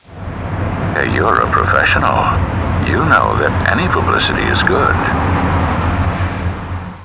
[BIZARRE TELEPHONE CALLS BEING RECEIVED BY SCOTLAND YARD! Available to THE LONDON SUN & NEWS OF THE WORLD readers only!]
Written transcripts of audio clues (TXT files) are available for users without sound-cards -- but where possible, we recommend downloading the sound files, because the background sounds and audio subtleties can be helpful in formulating your theory!
1601-Mystery Voice (WAV Format Sound File-77 KB)